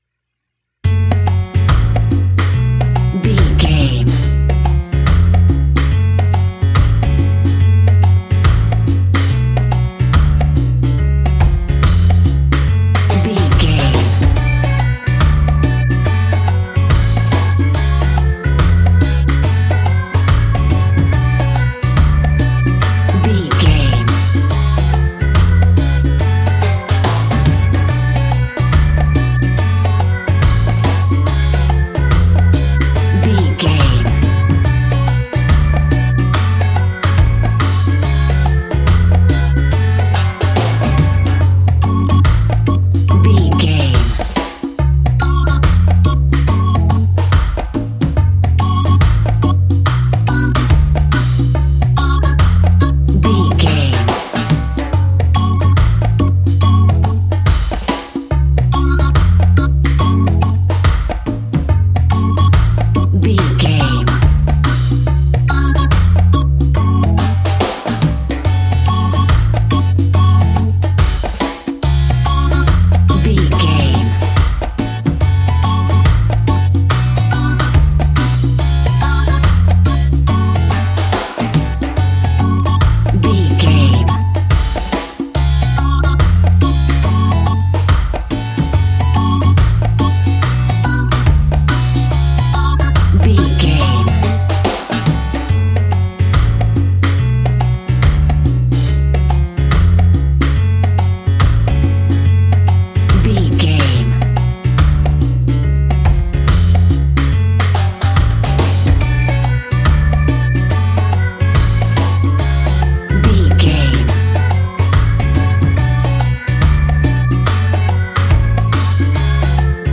Aeolian/Minor
F#
steelpan
drums
brass
guitar